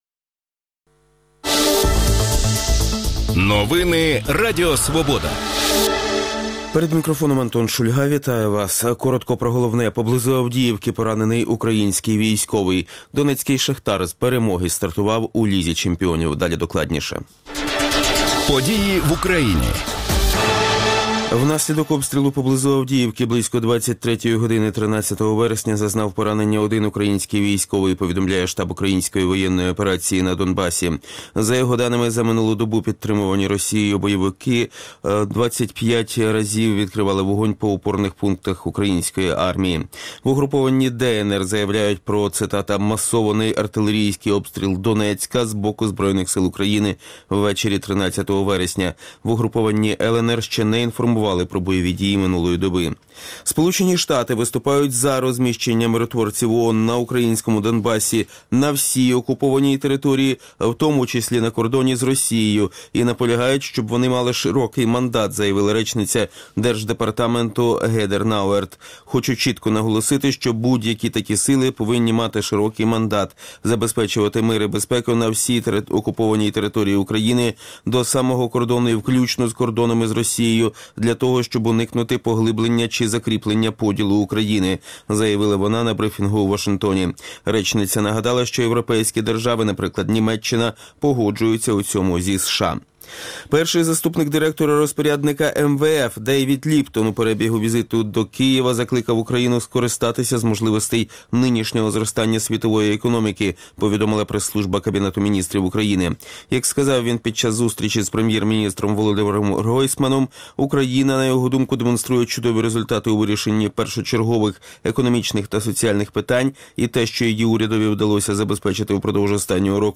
Про перспективи малого бізнесу в ефірі Ранкової Свободи говоритимуть голова Державної регуляторної служби України Ксенія Ляпіна та народний депутат («БПП») Оксана Продан.